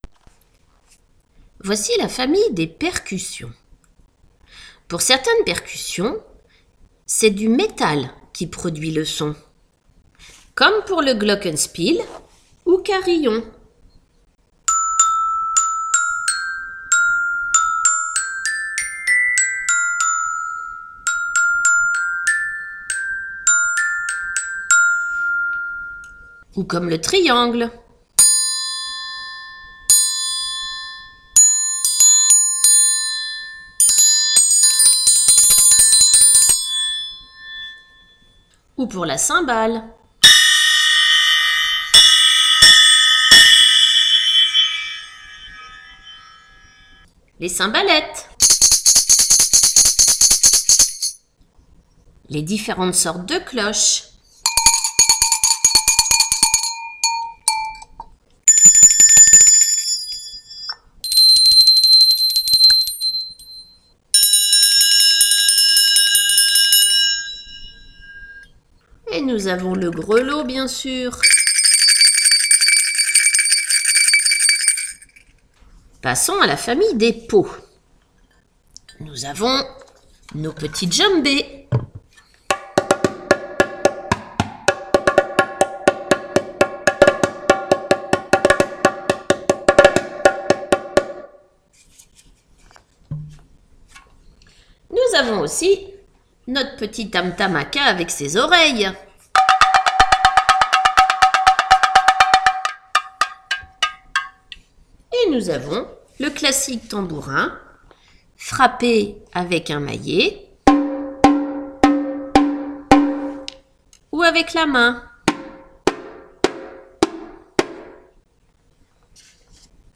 Pour aider votre enfant à affiner son écoute et à mémoriser des noms d’instruments, je vous présente la famille des percussions de notre classe.
PRESENTATION-DES-PERCUSSIONS-DE-LA-CLASSE.wav